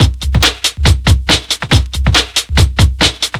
ELECTRO 01-R.wav